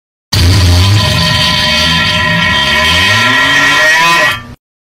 8. Долгий пук